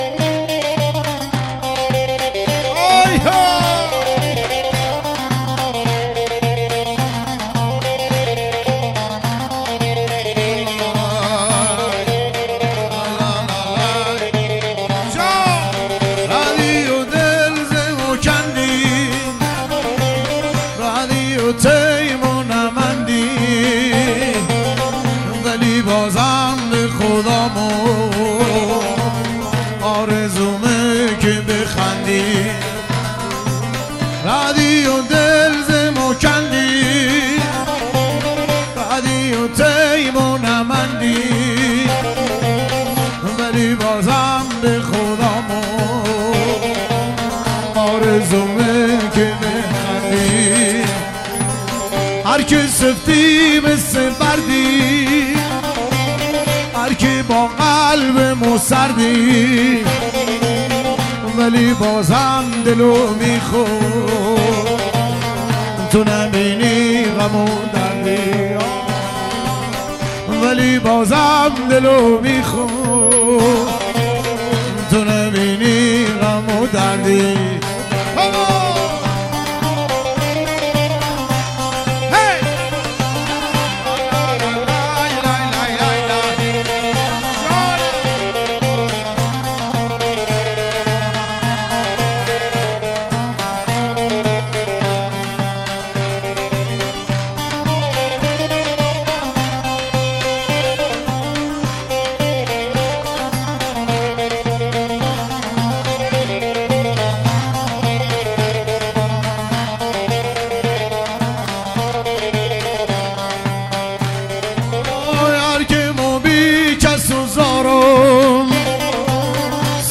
عاشقانه غمگین محلی لری عروسی